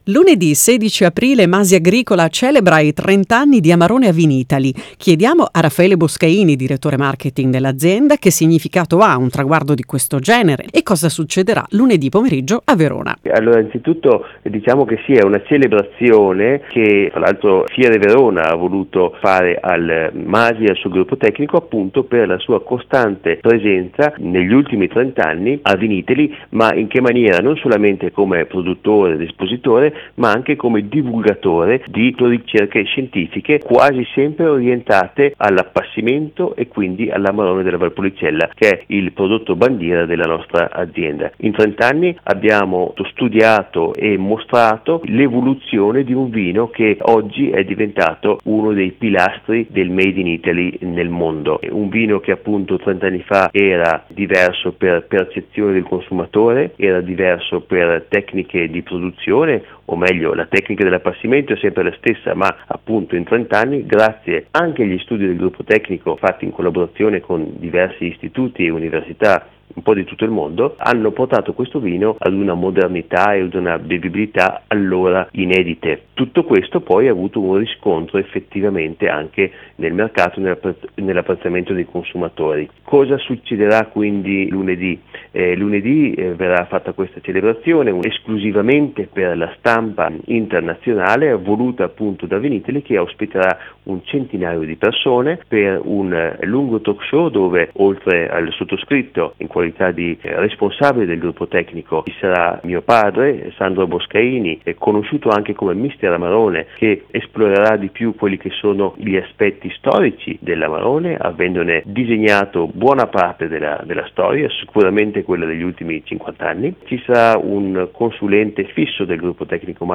Per noi è stata l’occasione di contattare l’azienda e fare quattro chiacchere molto piacevoli sull’Amarone